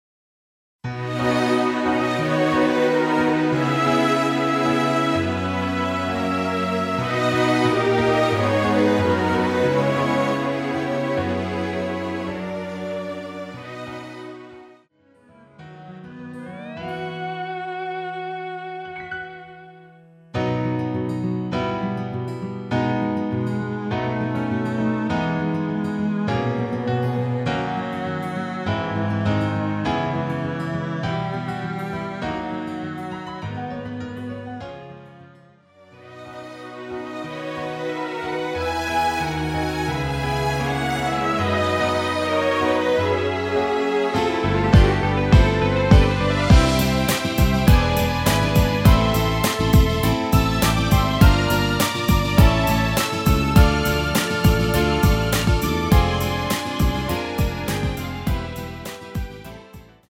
MR 대부분의 남성분들이 부르실수 있는키로 제작 하였습니다.
원곡의 보컬 목소리를 MR에 약하게 넣어서 제작한 MR이며